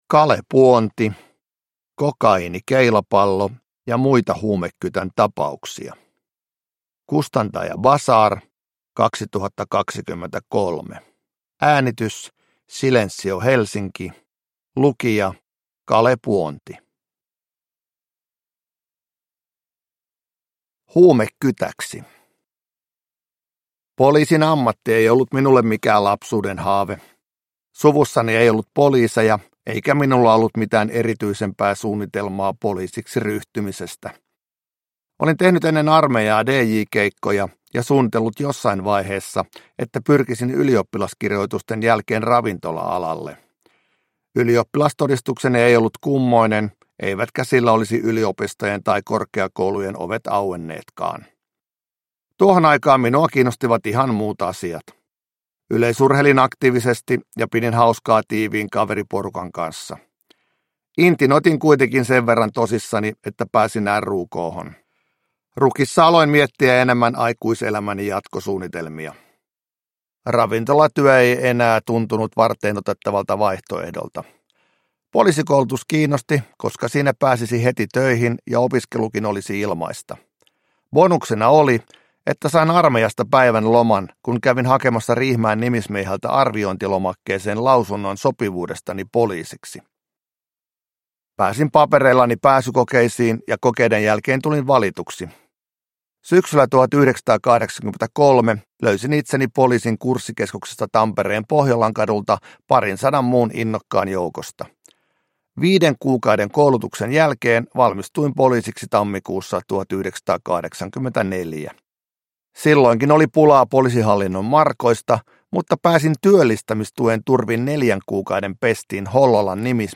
Kokaiinikeilapallo ja muita huumekytän tapauksia – Ljudbok – Laddas ner